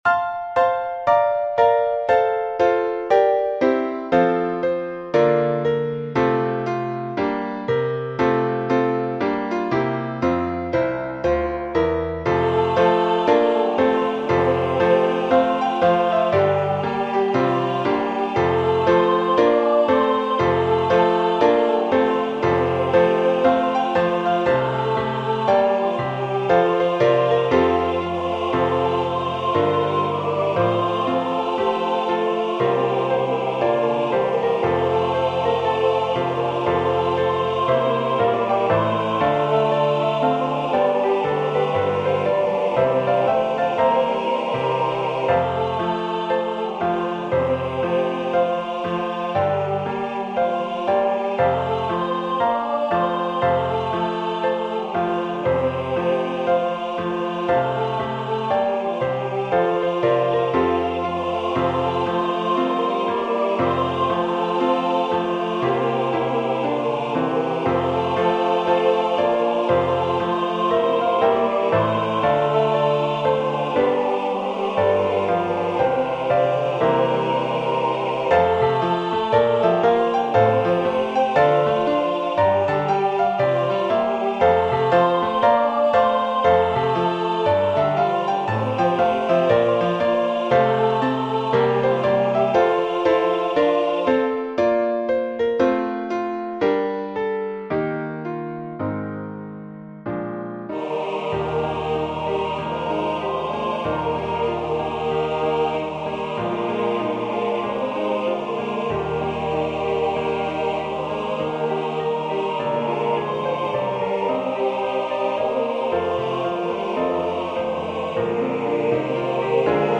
Go Tell It On the Mountain--HYMN #1208. SATB with Piano Accompaniment.
This is a fun African American Spiritual that has some spirit!